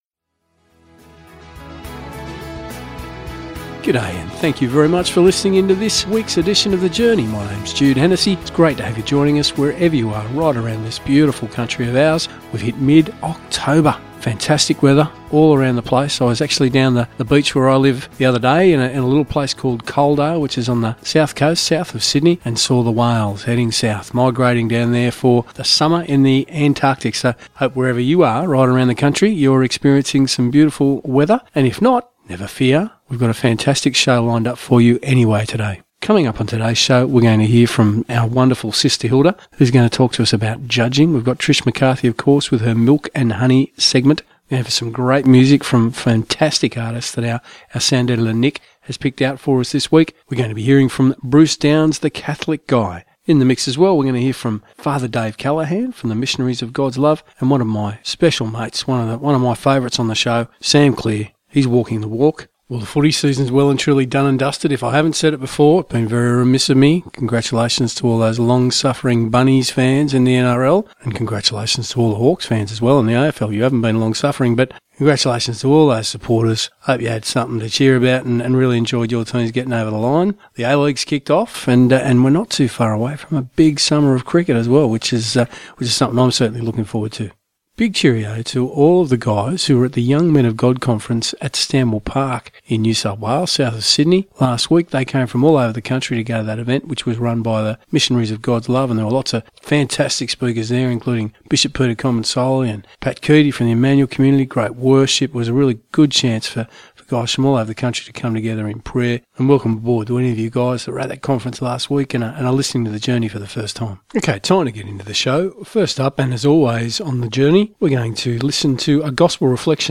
"The Journey" is a weekly Christian Radio program produced by the Catholic Diocese of Wollongong and aired on Christian Radio Stations around the country.
There are also regular interviews highlighting interesting things being done by people in the Church.